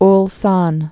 (lsän)